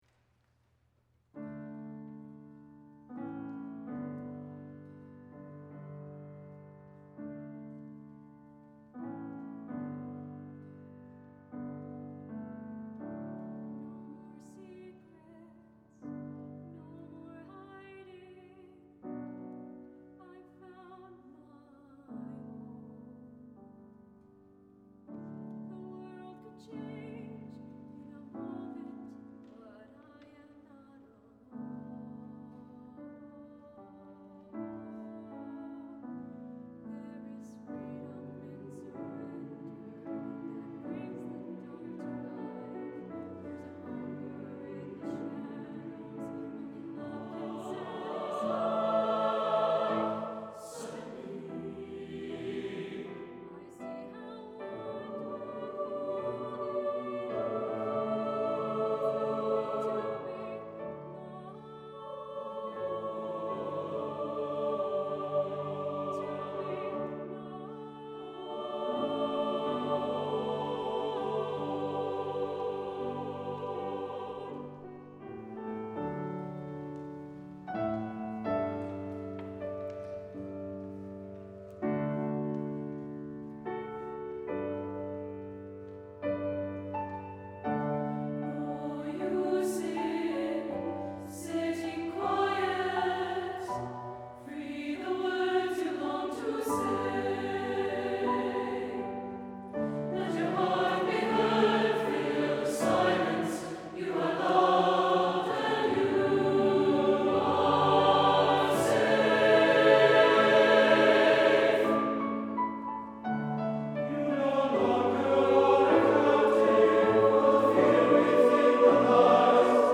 Affirming, melodic, accessible, and moving.
for SATB choir, solo & piano